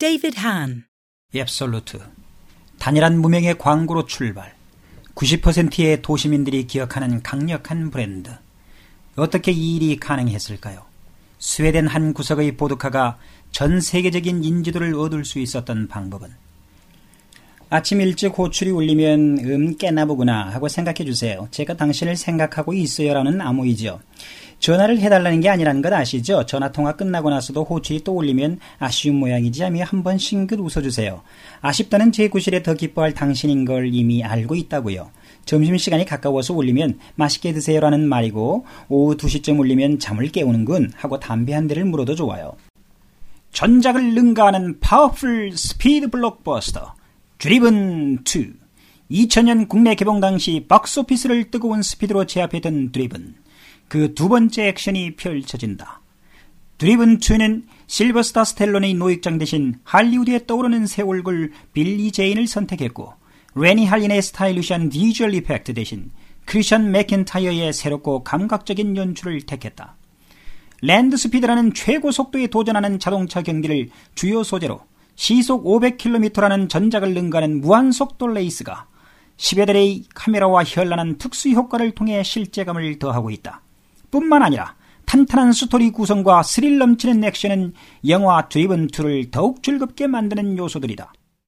Korean actor and voiceover artist